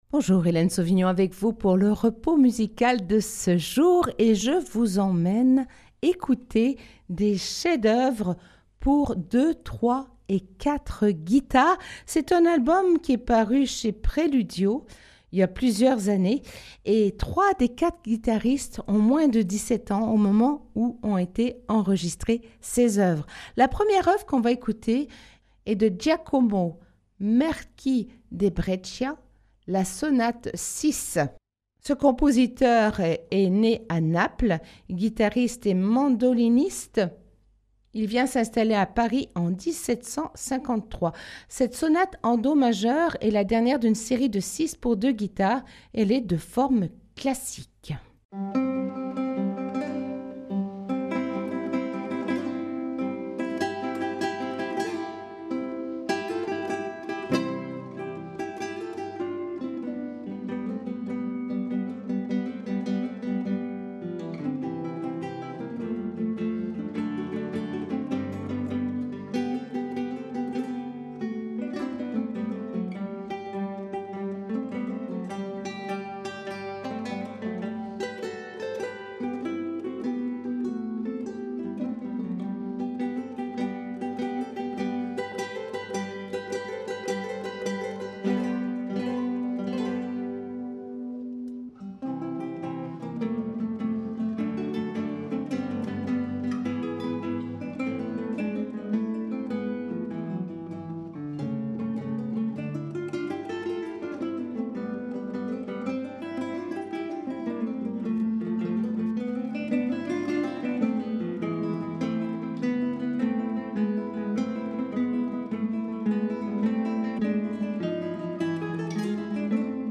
CHEFS D'OEUVRE POUR 2,3 ET 4 GUITARES